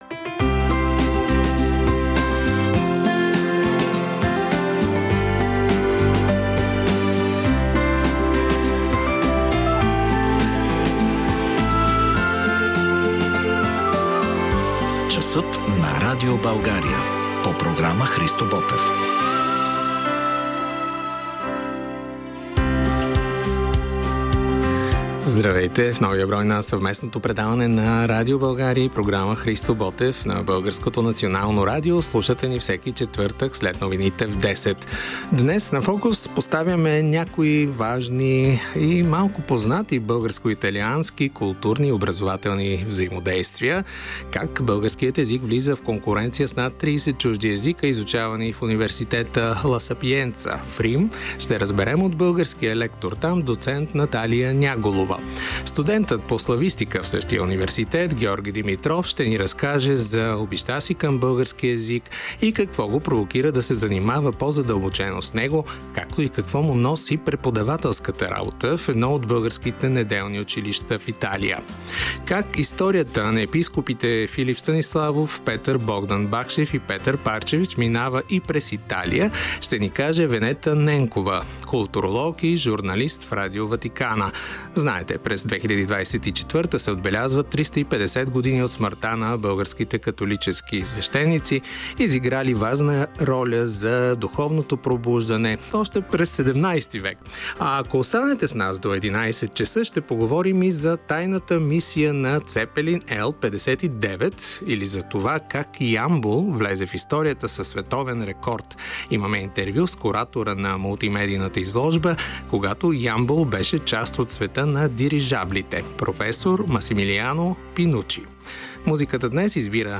🔵 За тайната мисия на цепелин L 59 или как Ямбол влезе в историята със световен рекорд? Интервю с проф.